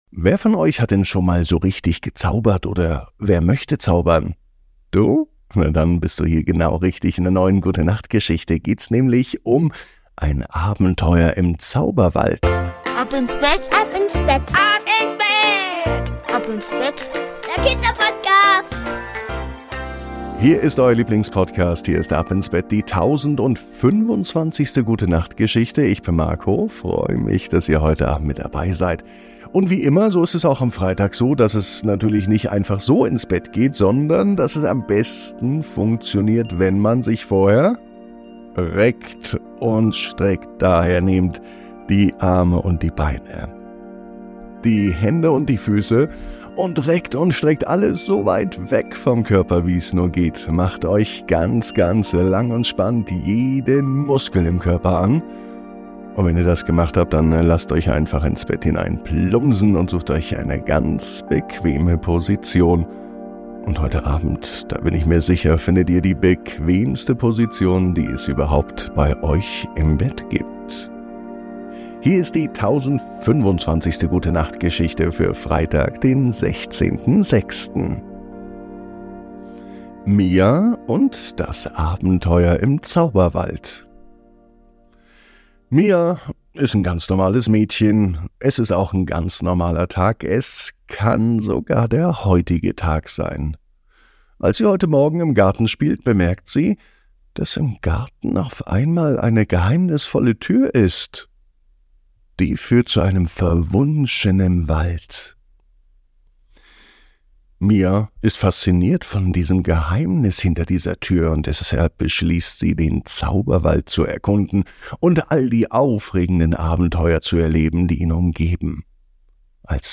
Die Gute Nacht Geschichte für Freitag